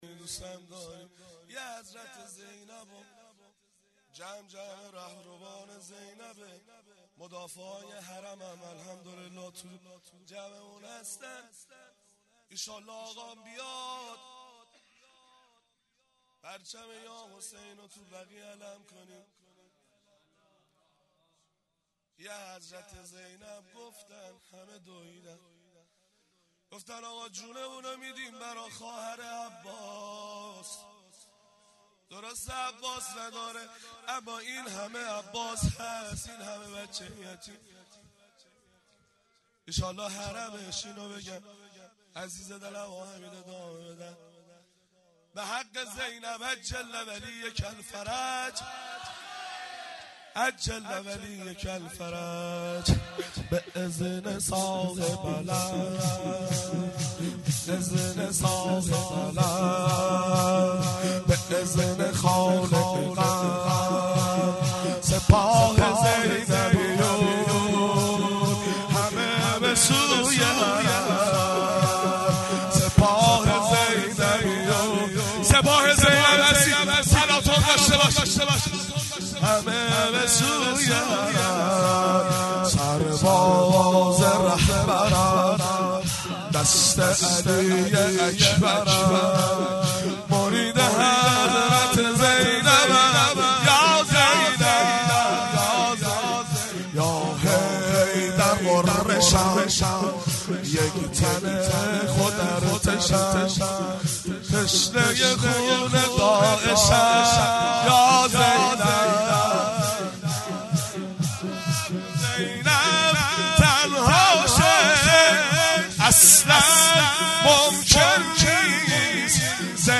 مراسم روز 28 صفر